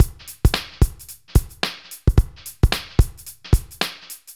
Index of /90_sSampleCDs/AKAI S6000 CD-ROM - Volume 4/Others-Loop/BPM110_Others2